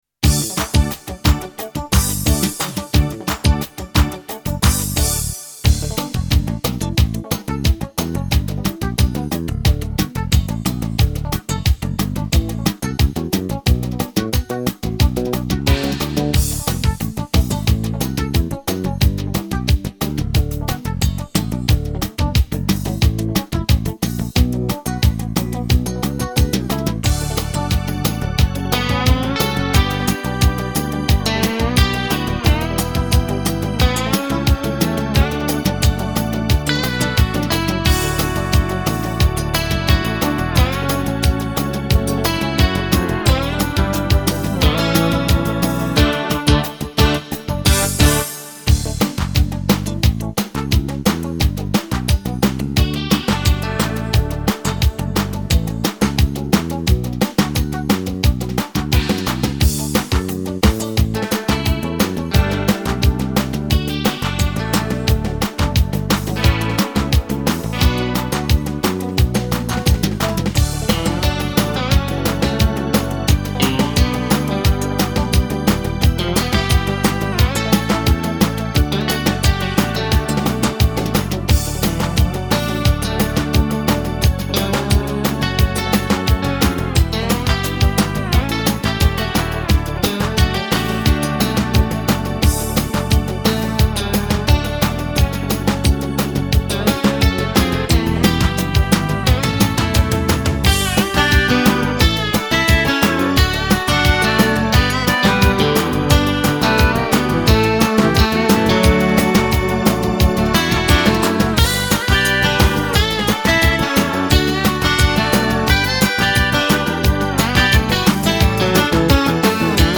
Украинские